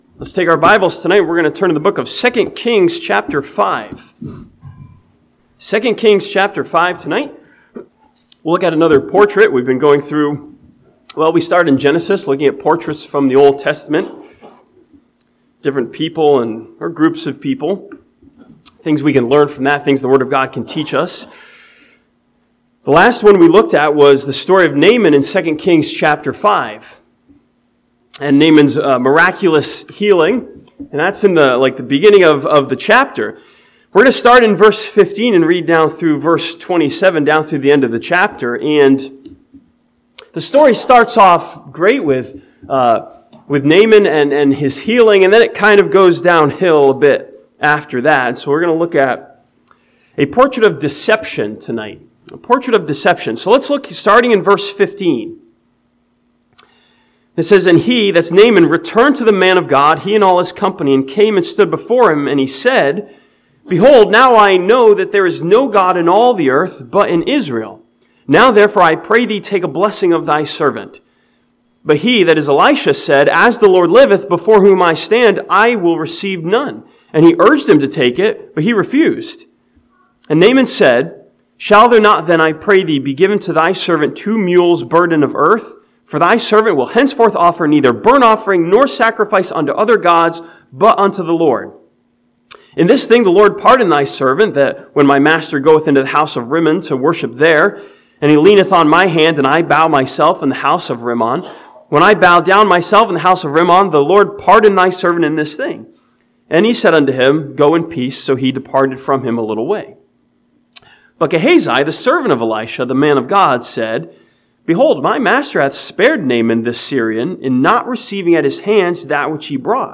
This sermon from 2 Kings chapter 5 studies Elisha's servant Gehazi who fall for the deception of sin's temptation.